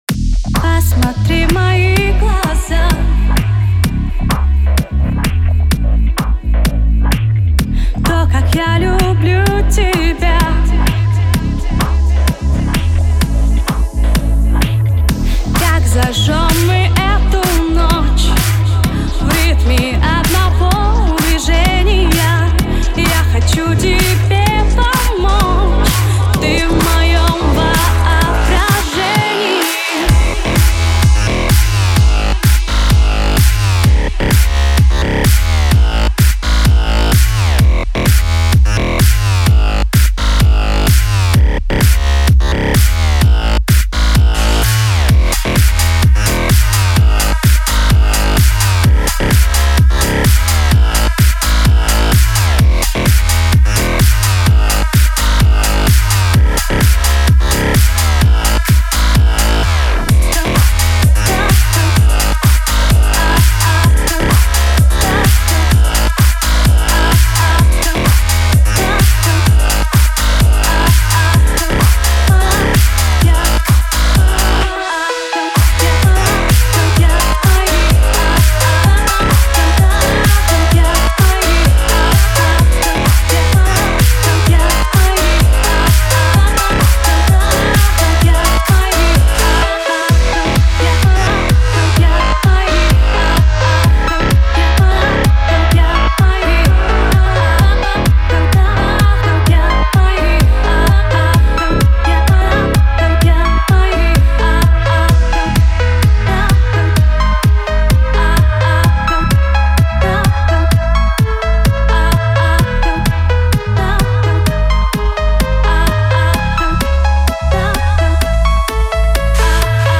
Электронная попса с русским вокалом)